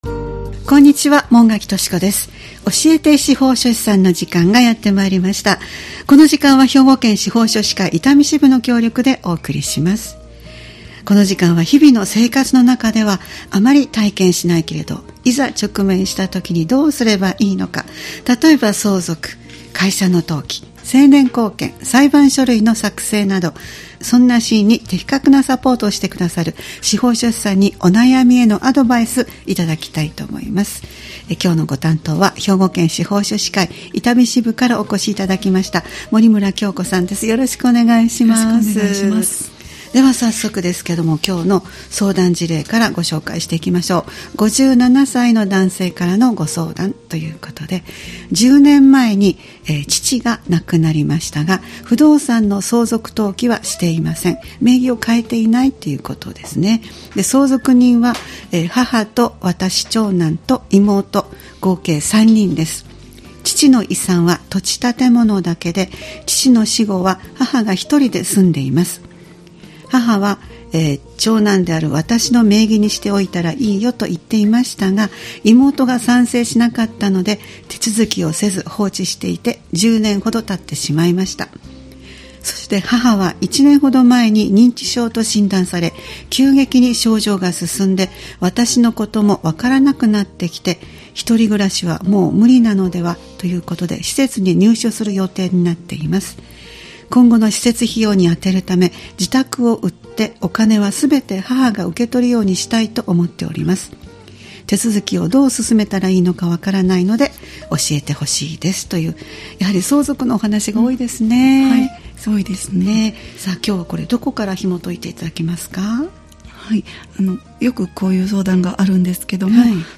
毎回スタジオに司法書士の方をお迎えして、相続・登記・成年後見・裁判書類の作成などのアドバイスを頂いています。